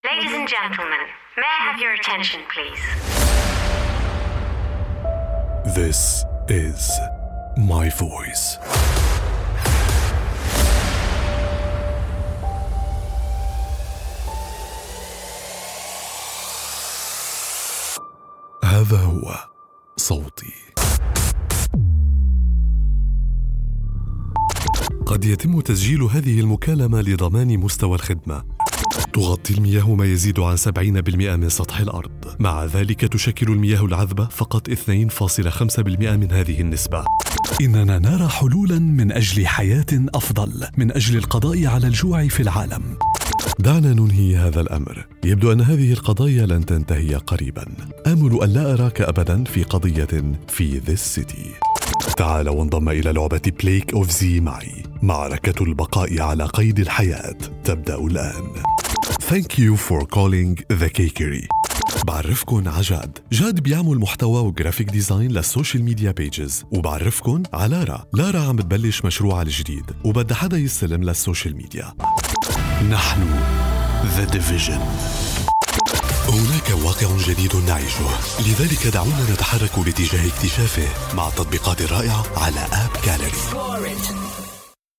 Vocal Styles:
alluring, intimate, rich, sultry
Vocal Age:
My demo reels